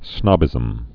(snŏbĭzəm)